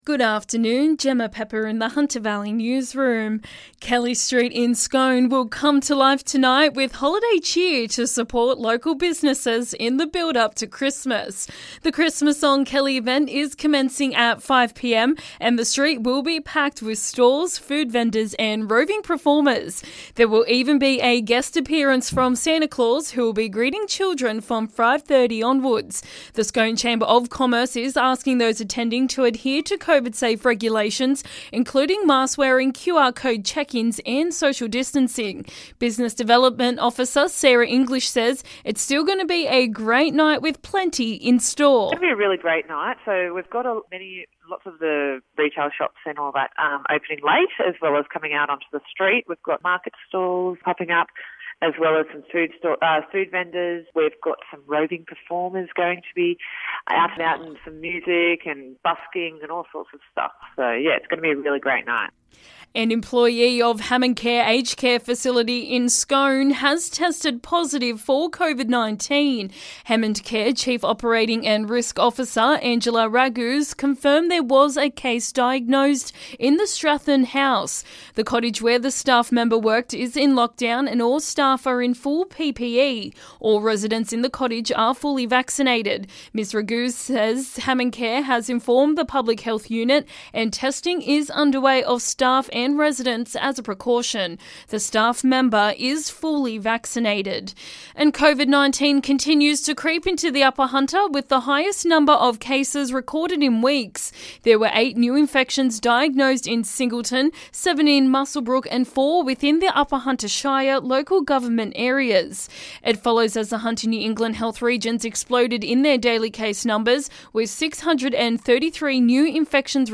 LISTEN: Hunter Valley Local News Headlines